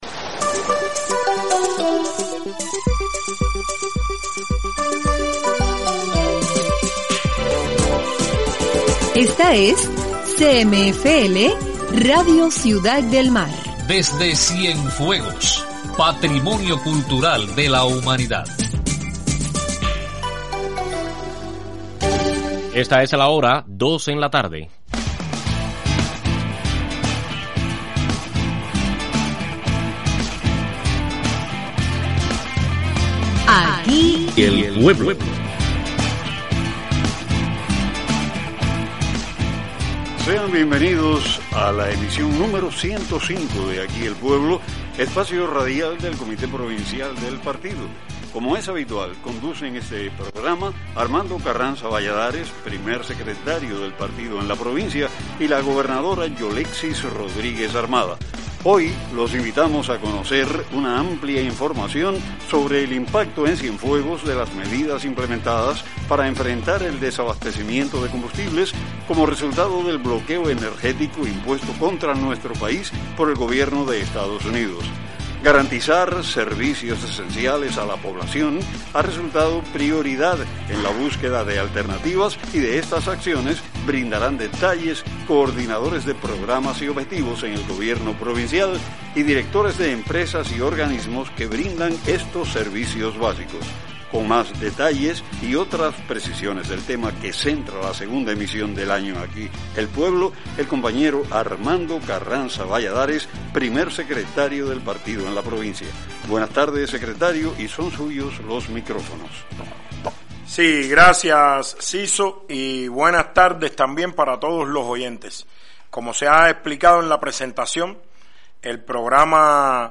Directores de organismos y coordinadores de programas y objetivos del Gobierno provincial, brindan pormenores en el programa Aquí el pueblo, de Radio Ciudad del Mar, acerca del cumplimiento de las medidas adoptadas para enfrentar las restricciones con el combustible, como consecuencia del cerco energético del gobierno de Estados Unidos contra Cuba.